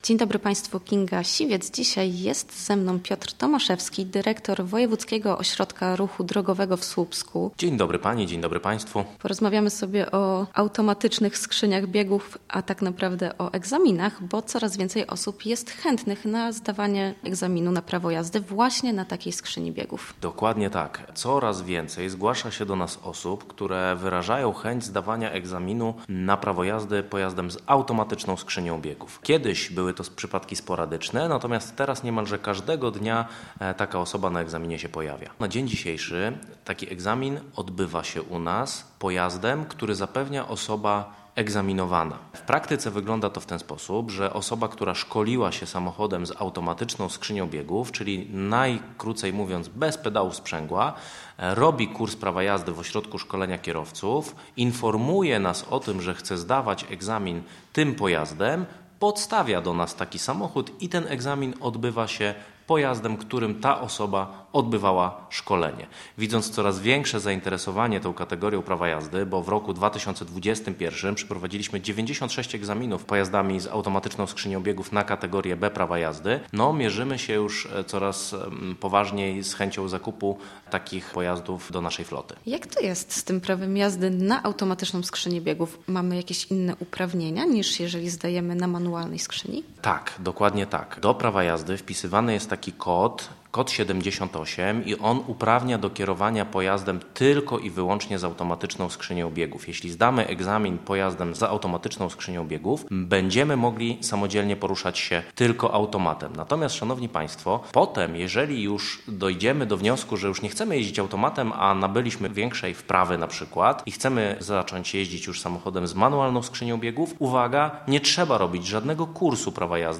coraz więcej chętnych do zdawania na prawo jazdy samochodem z automatyczną skrzynią (posłuchaj rozmowy)